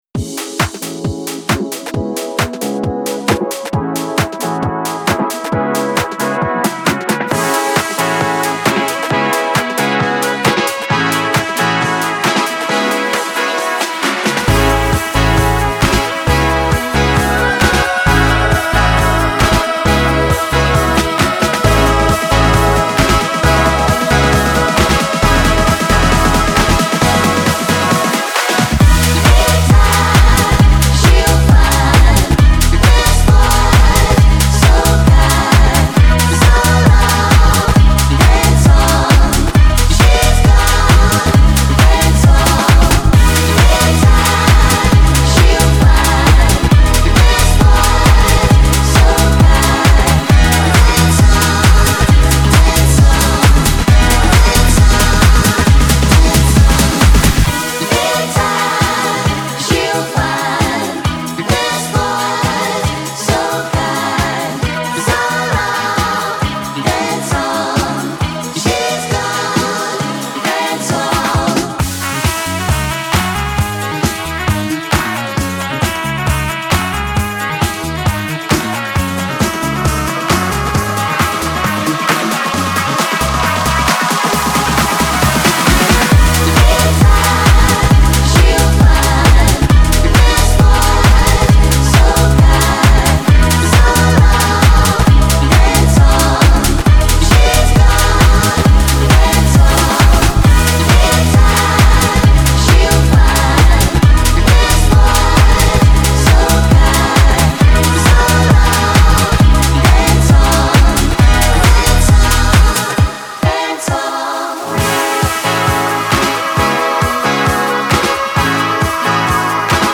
• Жанр: House